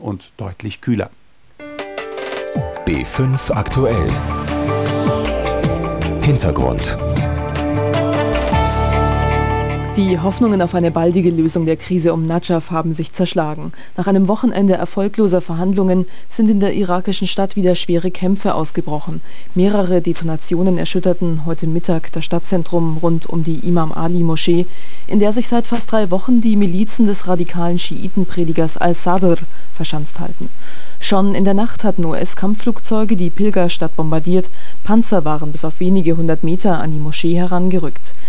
Nachfolgendes Audiobeispiel demonstriert die Aufnahme einer Datei über das integrierte Radio.
Für die Radioaufnahme wurden keine besonderen Einstellungen gewählt.
Die Aufnahmequalität ist absolut überzeugend.
Radioaufnahme (2,33 MB)